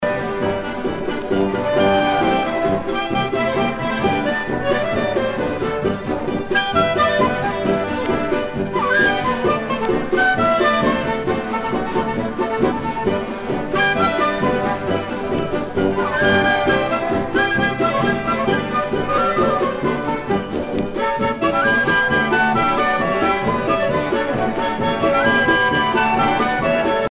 danse : polka
Pièce musicale éditée